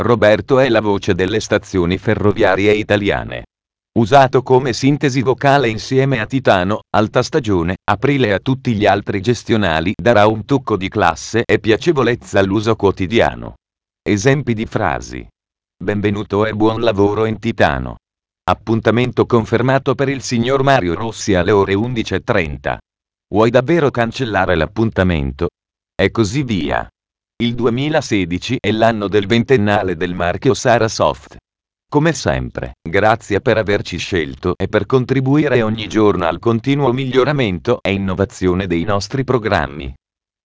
E' la pronuncia di messaggi con voce umana sintetica, ad esempio "Benvenuto e buon lavoro" all'apertura del programma, "Confermi la cancellazione ?" in caso di richiesta di cancellazione di un dato da un archivio, eccetera.
testo pronunciato con la voce "Roberto"
esempio-roberto.wav